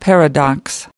Paradox.mp3